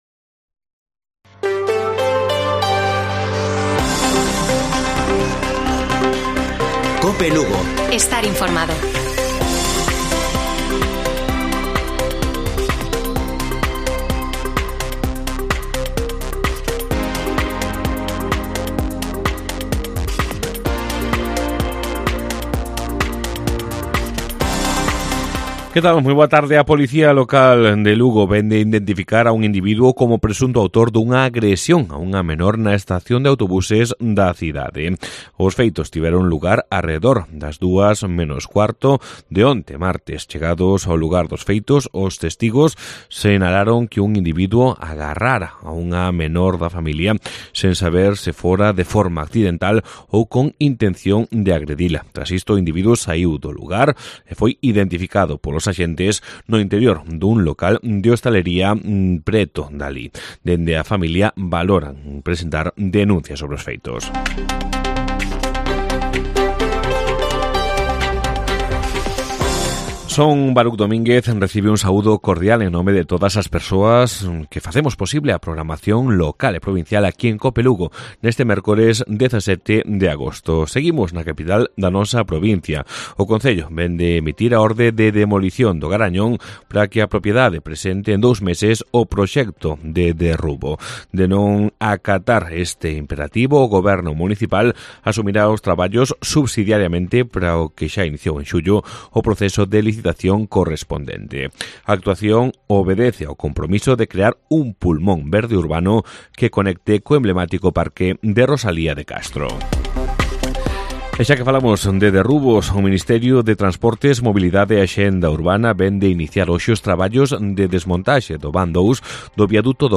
Informativo Mediodía de Cope Lugo. 17 DE AGOSTO. 14:20 horas